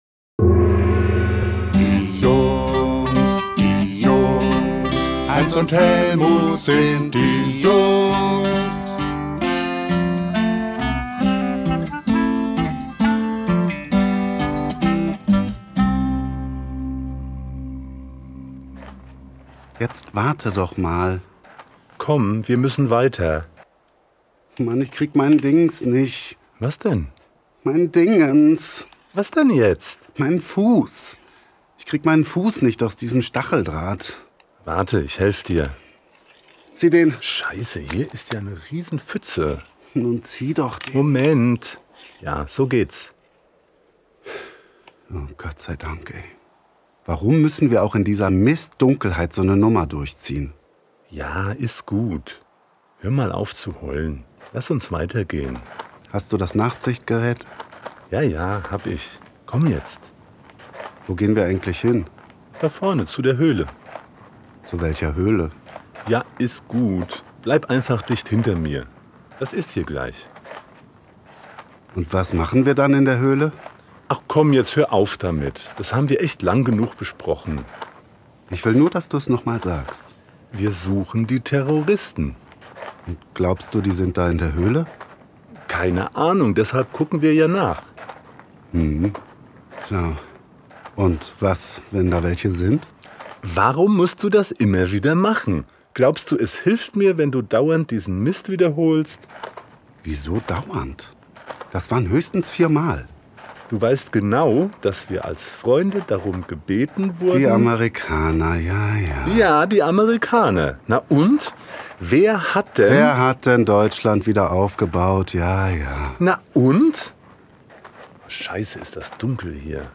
Klarinette
Zentralstudio Mainz